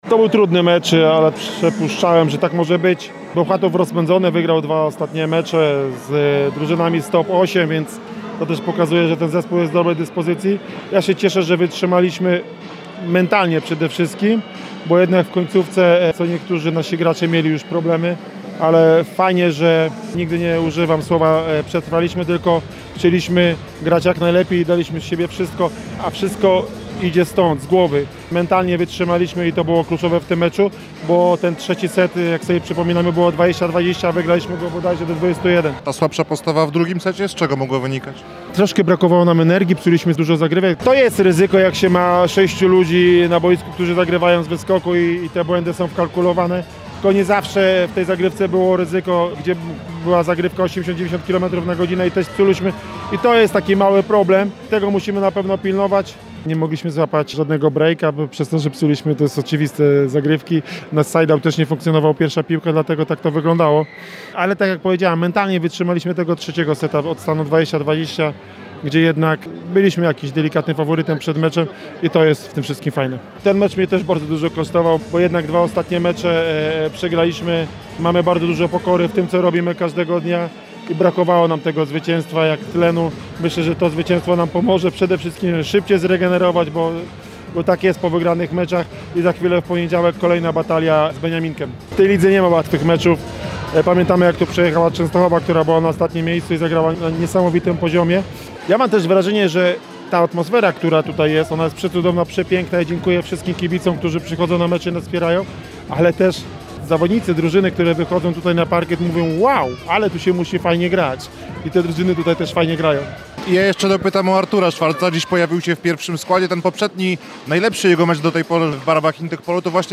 – ocenił spotkanie trener akademików, Daniel Pliński.